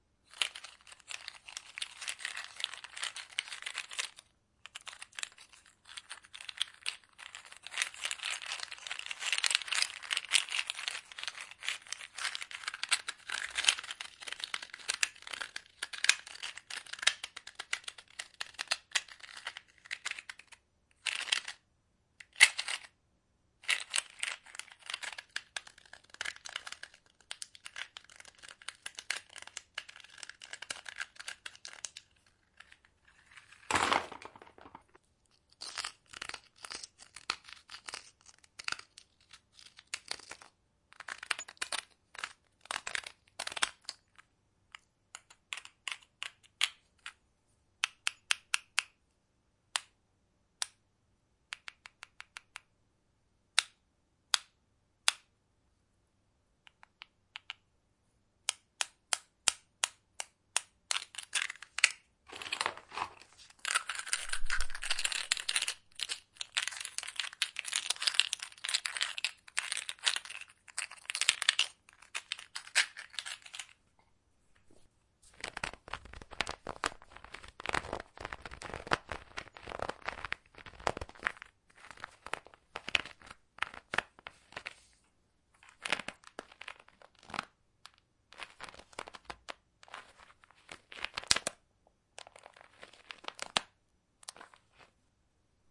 厨房 " 核桃的摩擦一起摩擦
描述：用力摩擦核桃。录音设备：TascamDR40（内置麦克风）
Tag: 在一起 木材 核桃 摩擦 摩擦